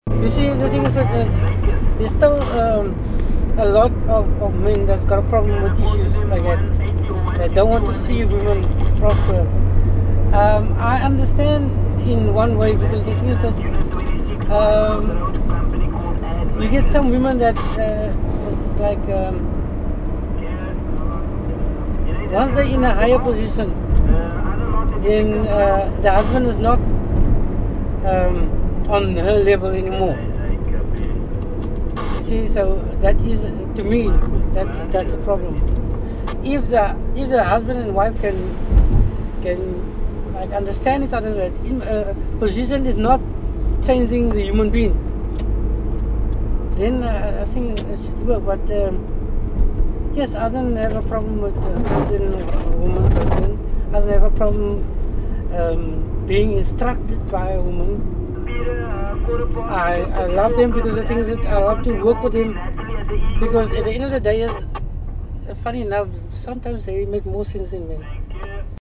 Taxi tales - Cape Town taxi drivers speak about feminism